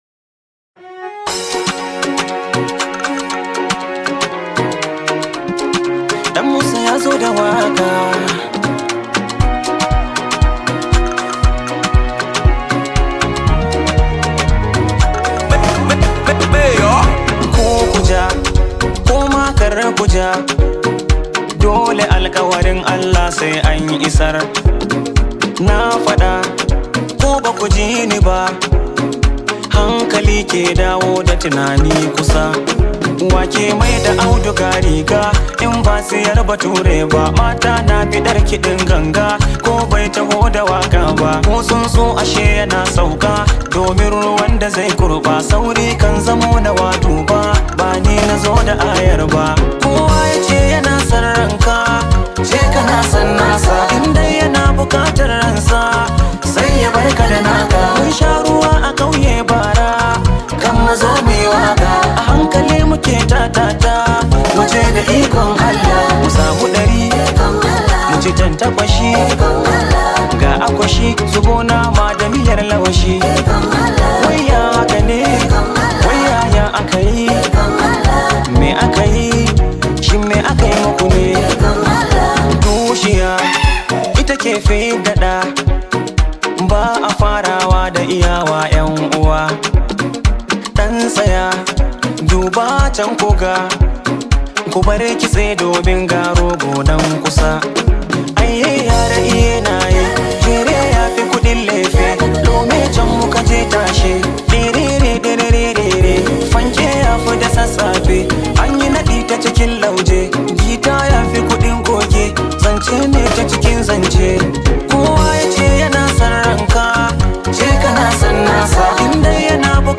Hausa Musics